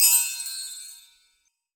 chimes_magic_bell_ding_2.wav